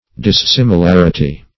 Dissimilarity \Dis*sim`i*lar"i*ty\, n.